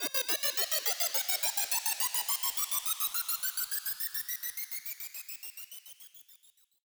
MB Trans FX (21).wav